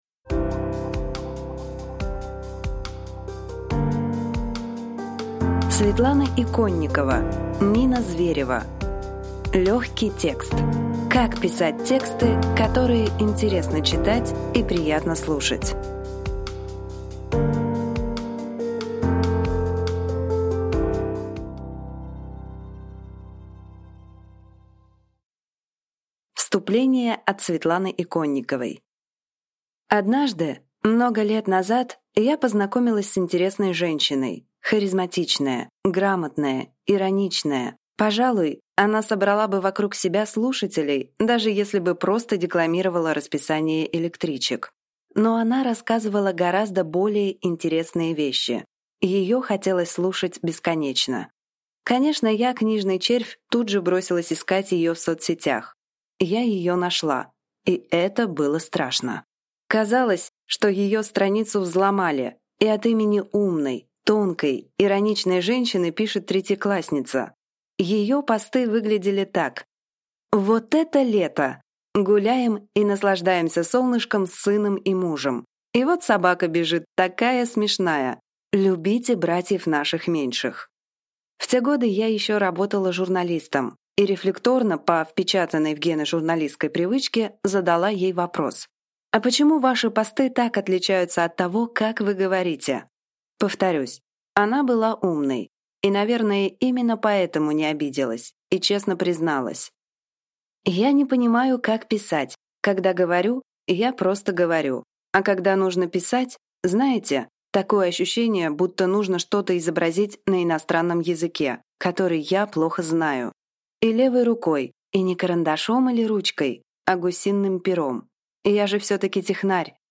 Аудиокнига Легкий текст. Как писать тексты, которые интересно читать и приятно слушать | Библиотека аудиокниг